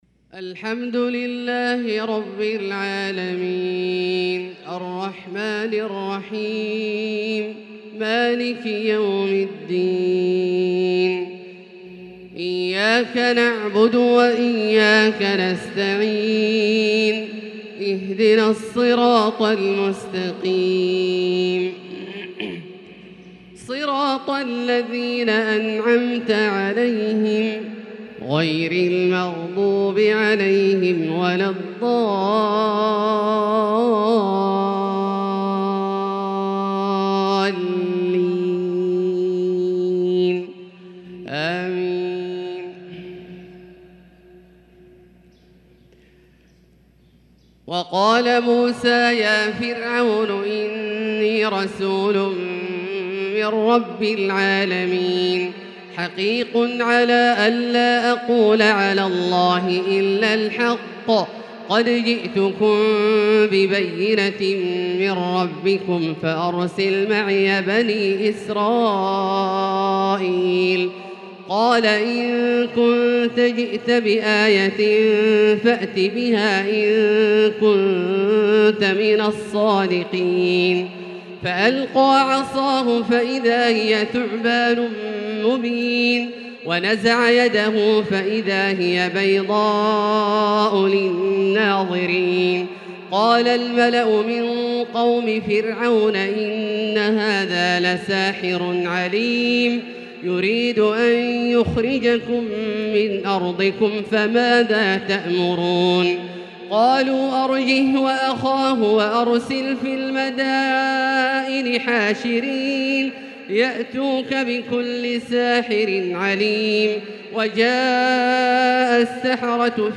فجر الثلاثاء 5-8-1443هـ من سورة الأعراف | Fajr prayer from Surat Al-A'raaf 8-3-2022 > 1443 🕋 > الفروض - تلاوات الحرمين